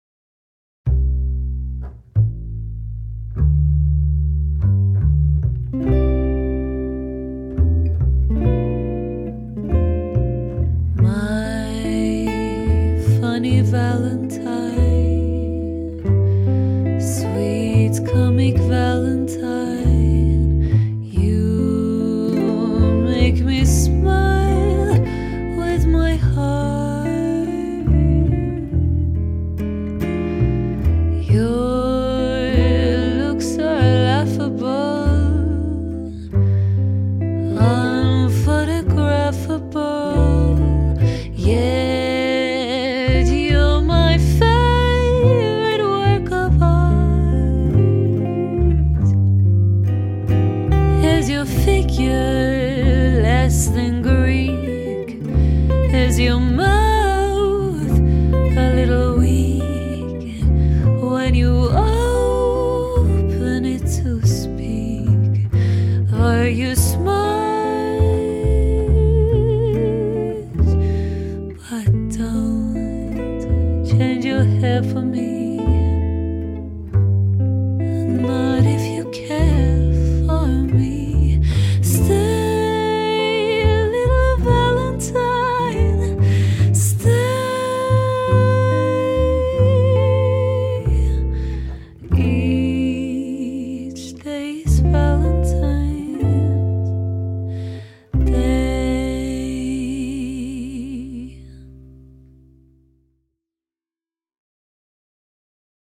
• Talented jazz trio with female vocals
Female Vocals, Double Bass, Guitar